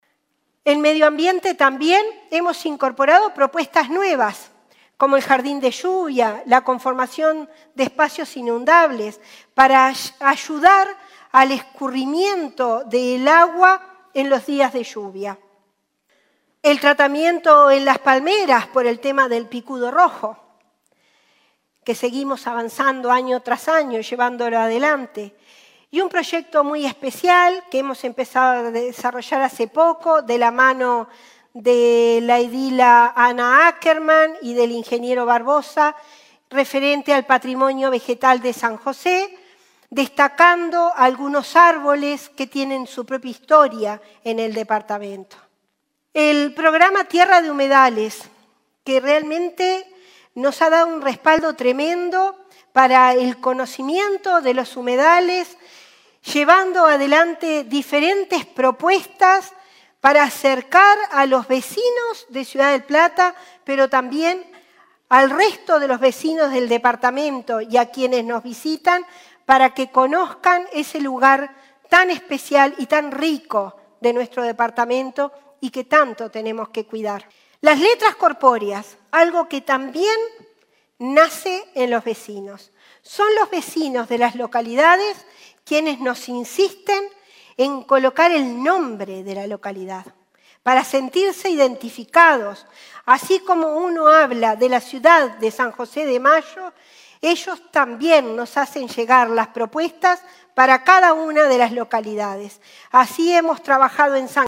En una ceremonia celebrada en el Teatro Macció en San José de Mayo,  se concretó este domingo el traspaso de mando en la Intendencia de San José.
Escuchamos un extracto de Bentaberri en su informe de este domingo cuando se focalizó en materia medioambiental…